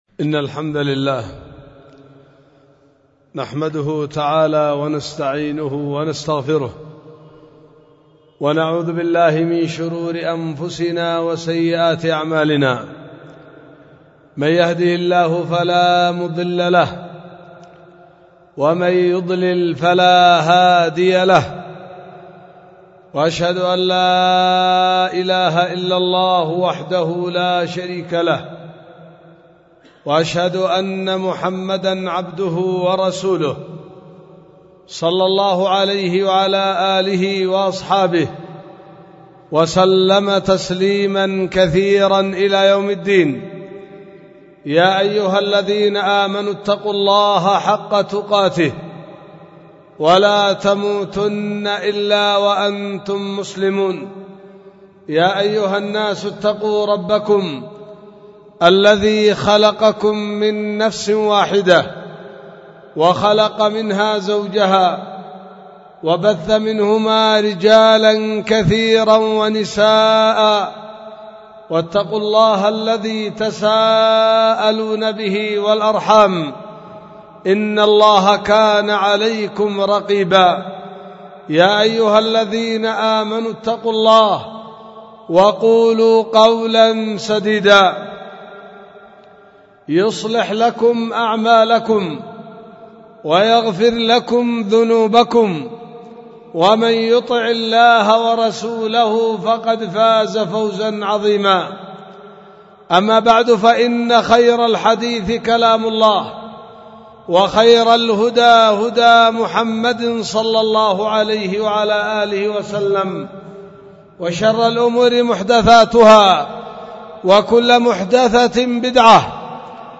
خطبة ألقيت في 24 ربيع الآخر 1444 هجرية في دار الحديث بوادي بنا – السدة – إب – اليمن